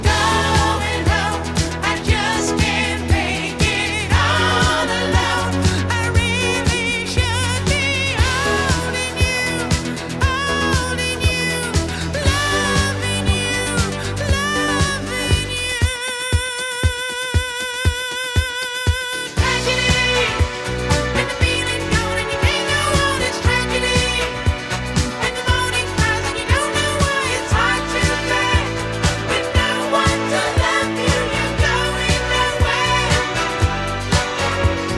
# Disco